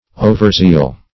Overzeal \O"ver*zeal\, n. Excess of zeal.